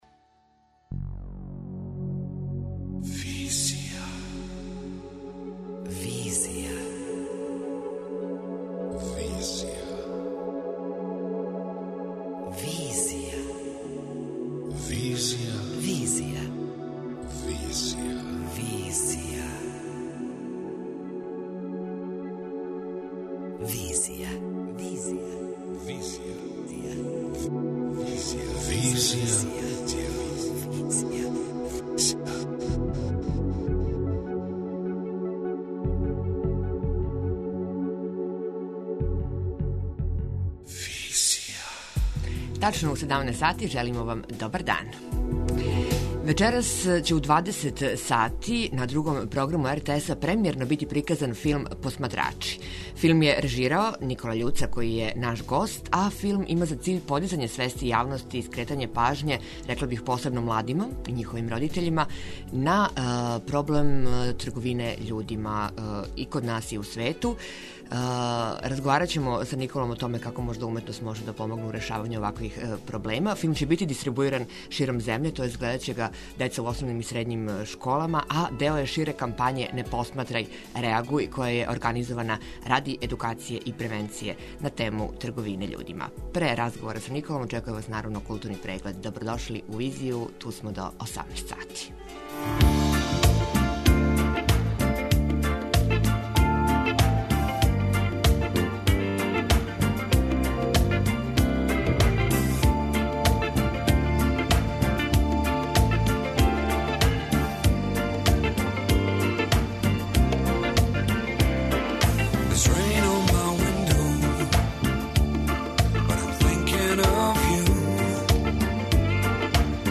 преузми : 27.41 MB Визија Autor: Београд 202 Социо-културолошки магазин, који прати савремене друштвене феномене.